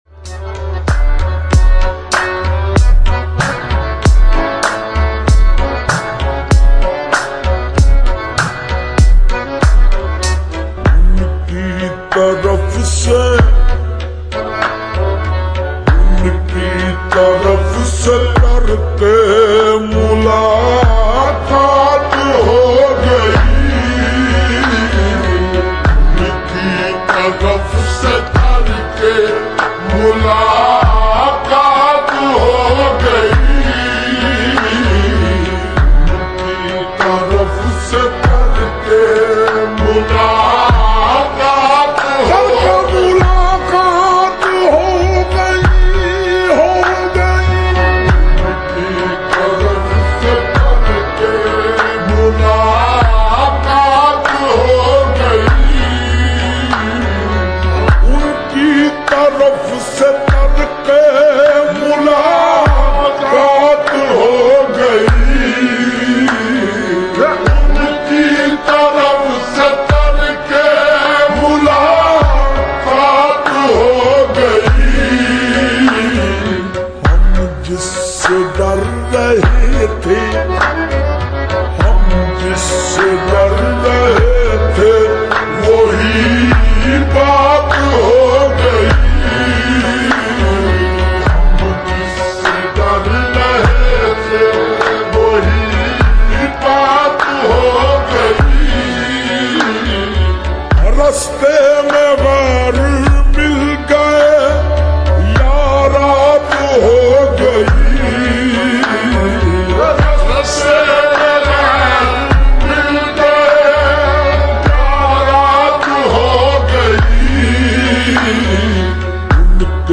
QWALI SLOWED AND REVERB